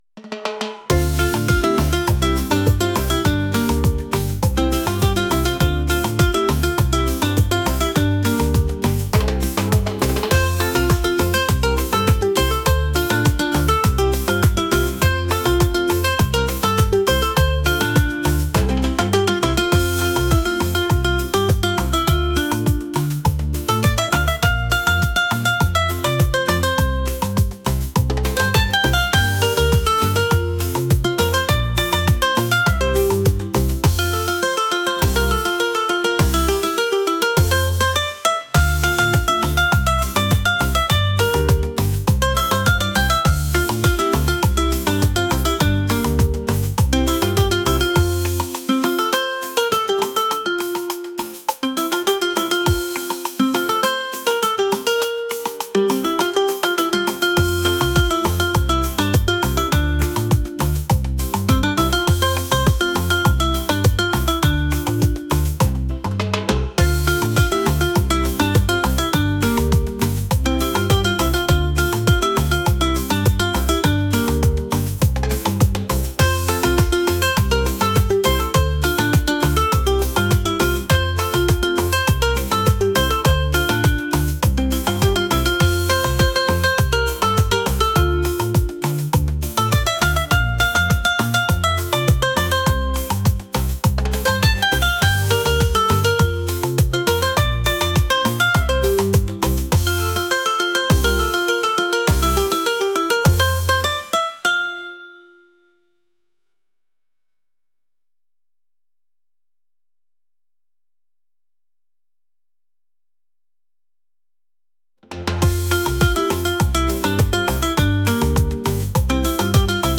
upbeat | latin